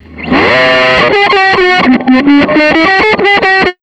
DJ130GUITR-R.wav